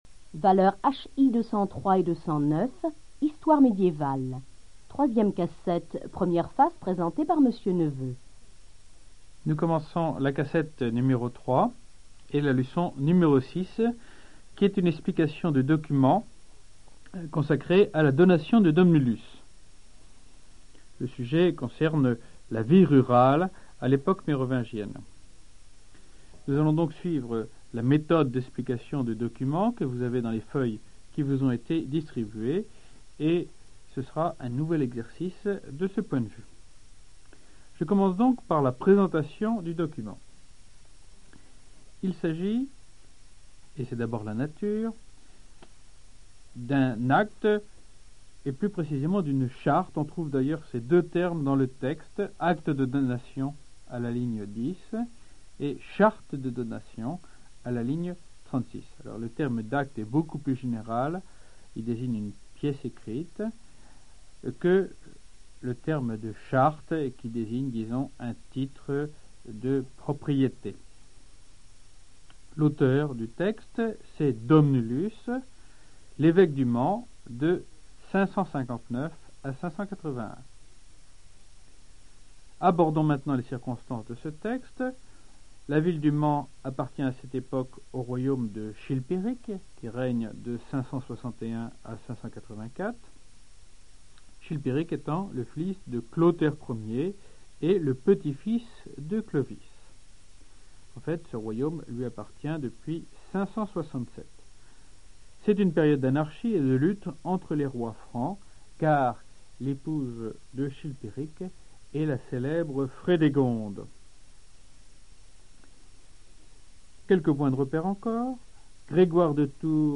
Archives cours audio 1987-1988